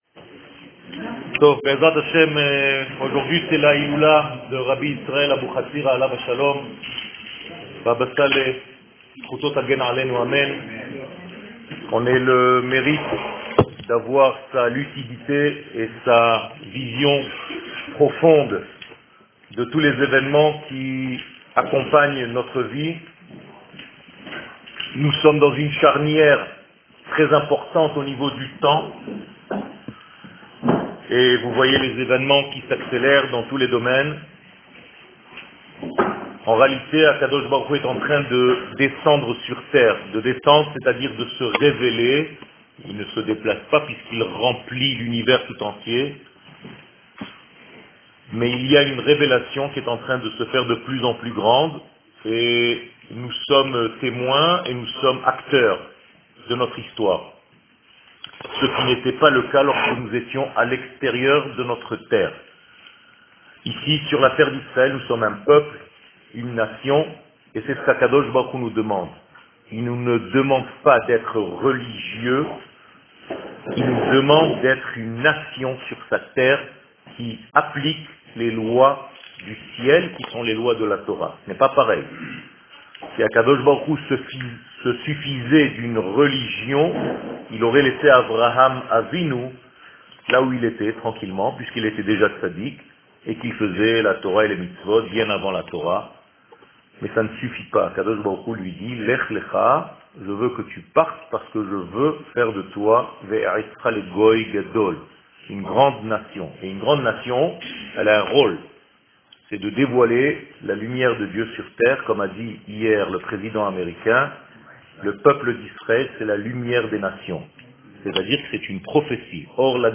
Ashdod - Hiloula de Rabbi Israel Abouhatzera
שיעור